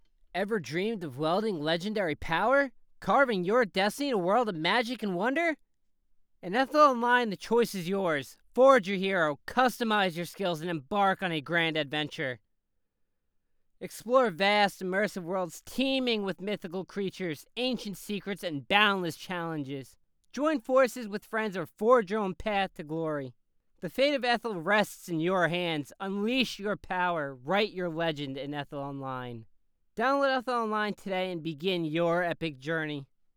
Online game advertisement
high tone. medium tone, new yorker,
Ranging from mid-high pitched tones, neardy, and nasally.
Microphone Rode NT1